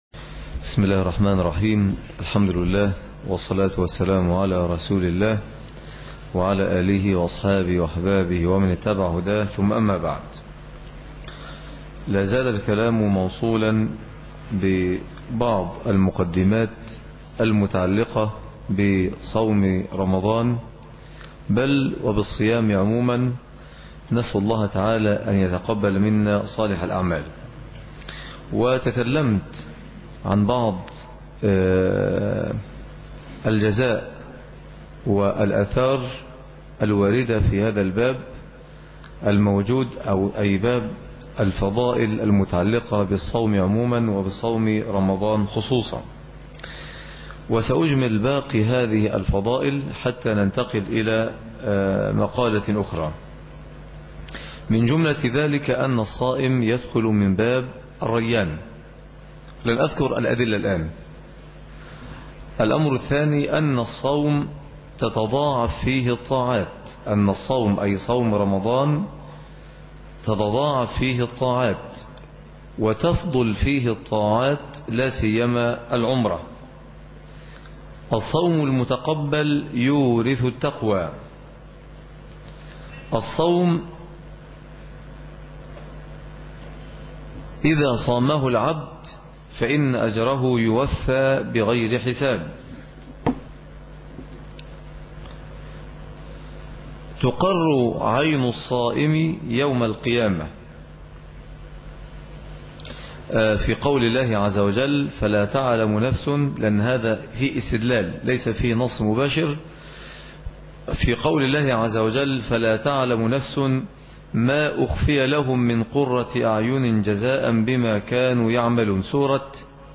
الدرس الثاني(أحكام الصيام _1430